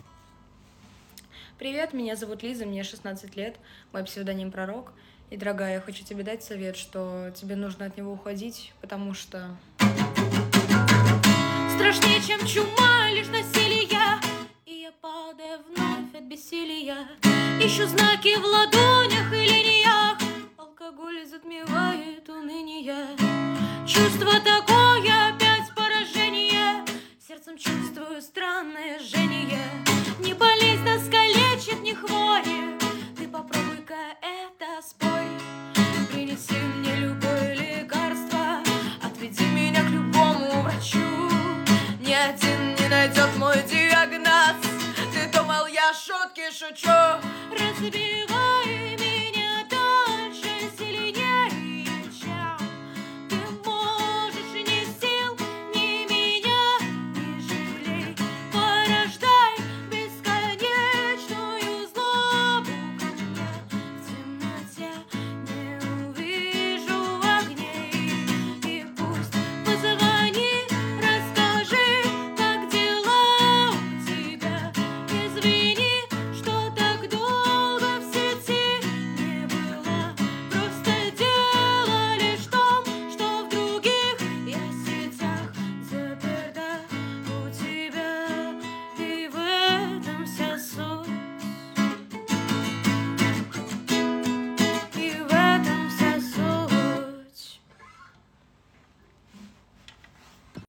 Рок музыка 2025, Рок